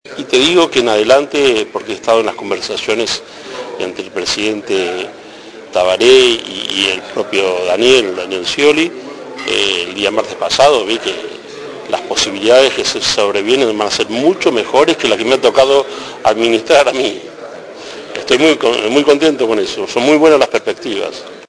En entrevista con Espectador al día, el diplomático sostuvo que el balance de la recomposición de relaciones es positivo, porque él llegó en momentos en donde no se hablaban prácticamente entre las dos partes.